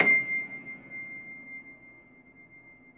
53c-pno22-C5.wav